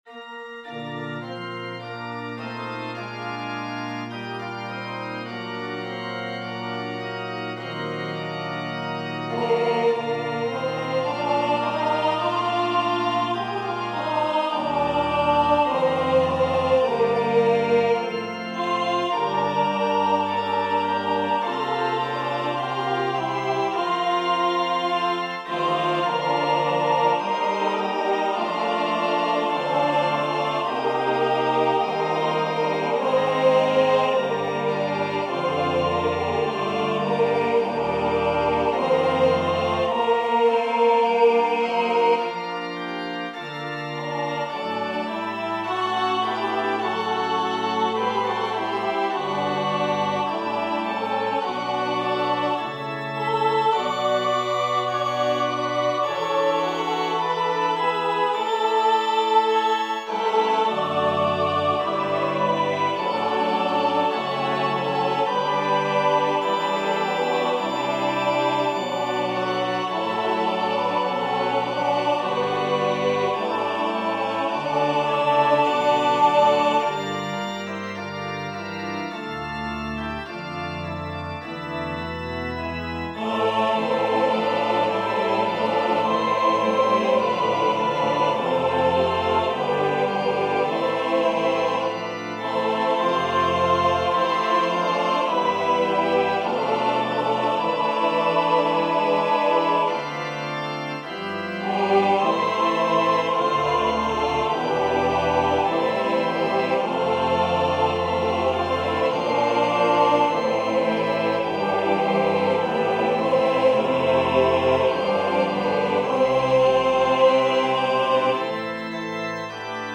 Organ or Piano Accompaniment.